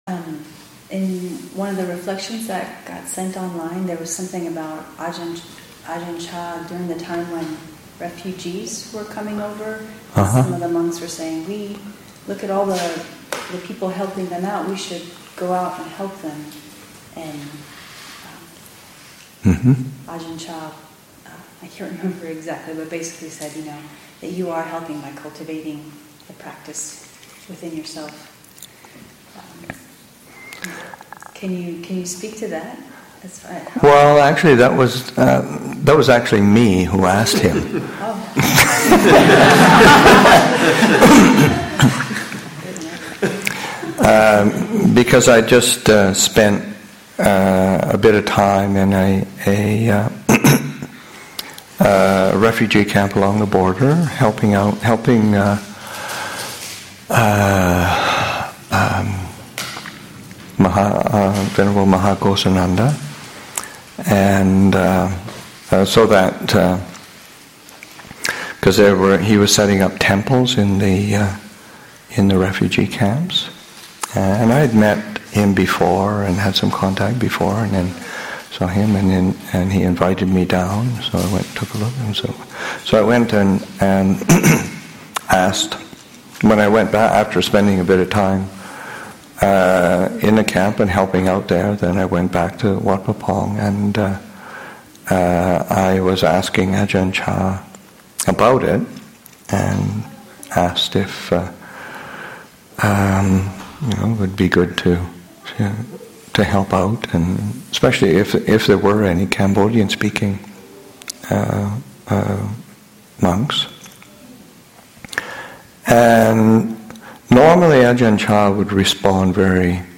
Upāsikā Day, Aug. 12, 2017
Abhayagiri Buddhist Monastery in Redwood Valley, California and online